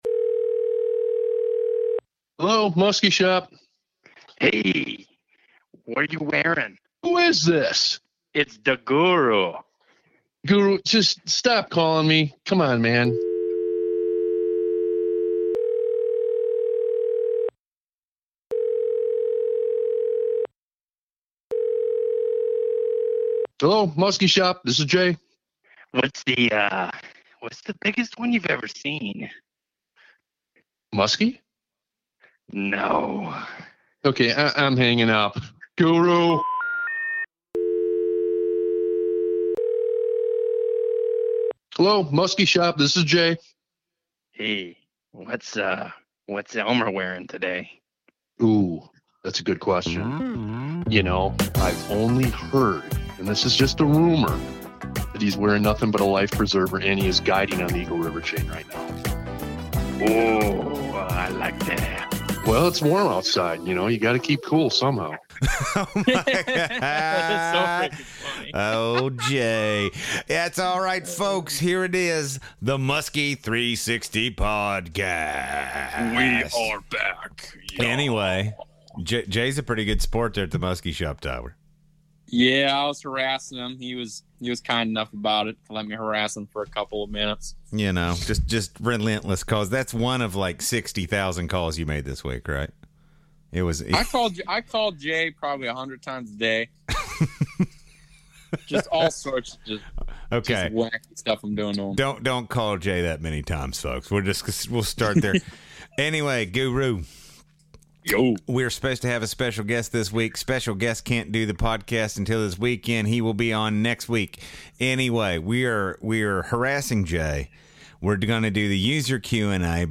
User Q&A